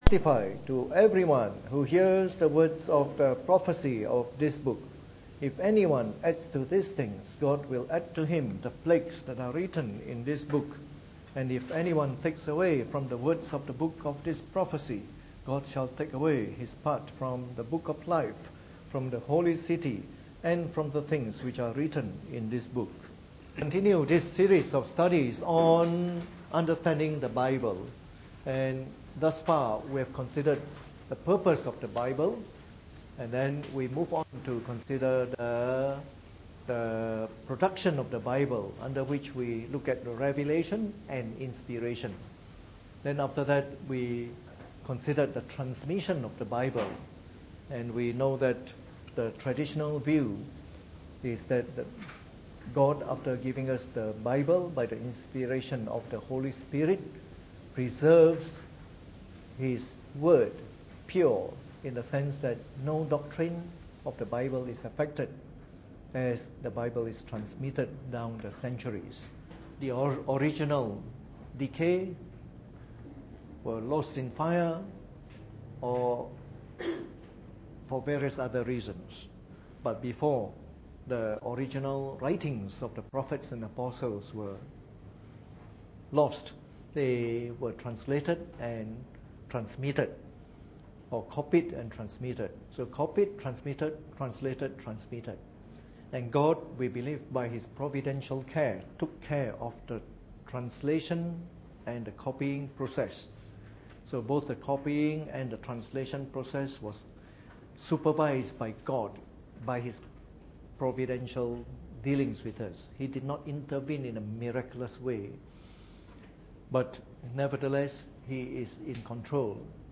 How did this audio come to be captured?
Preached on the 15th of April 2015 during the Bible Study, from our series of talks on Knowing the Bible.